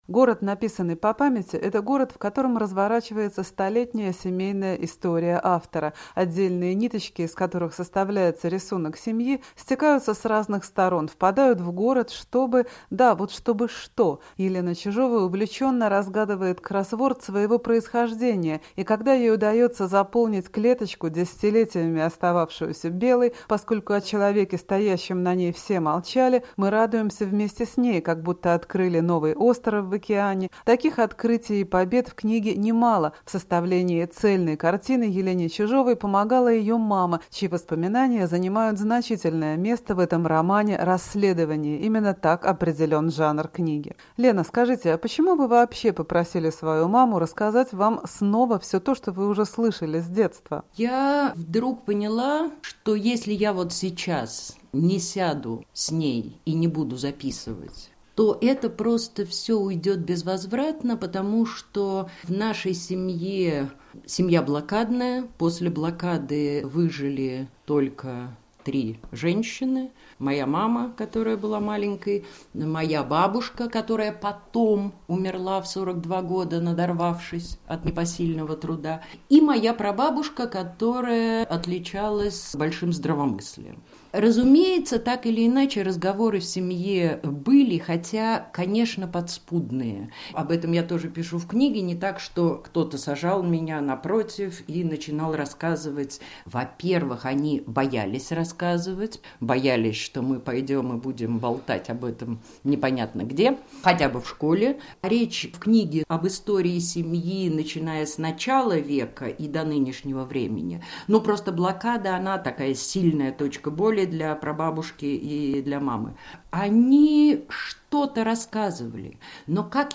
разговор с Еленой Чижовой в программе "Культурный дневник"